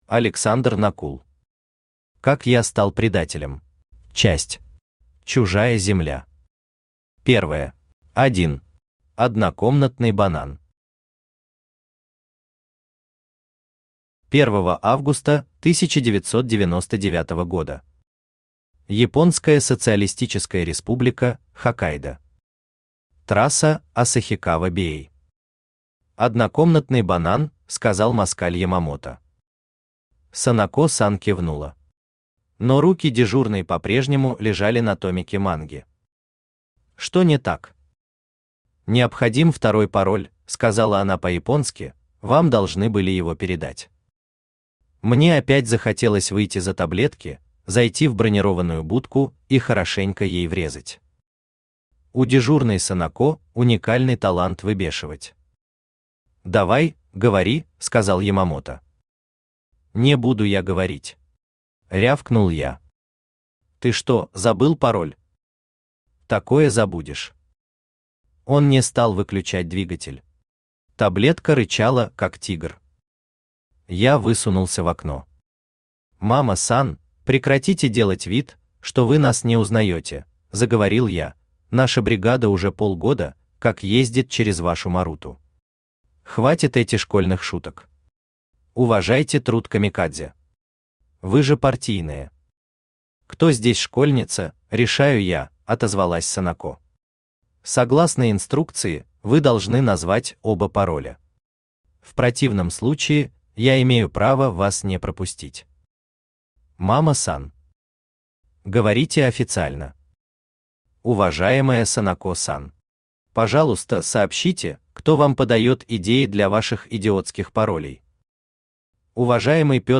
Aудиокнига Как я стал предателем Автор Александр Накул Читает аудиокнигу Авточтец ЛитРес.